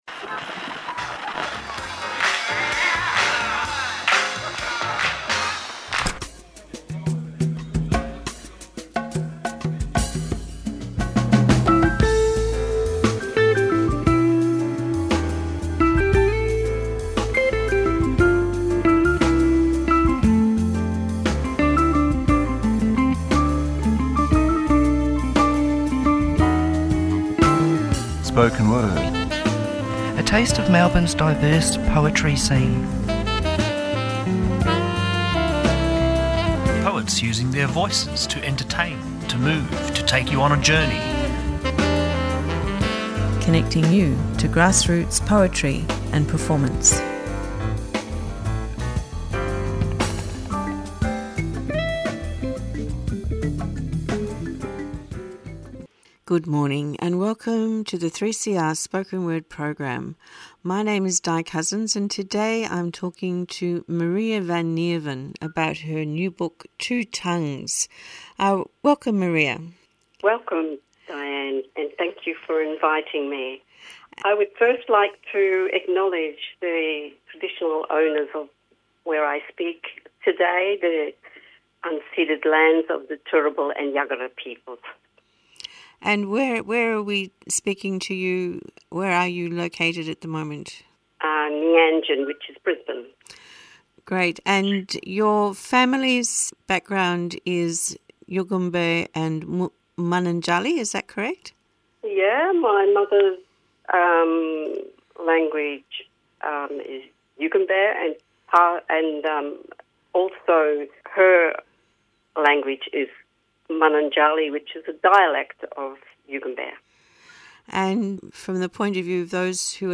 Tweet Spoken Word Thursday 9:00am to 9:30am A program dedicated to the eclectic world of poetry and performance. Guests are contemporary poets who read and discuss their works.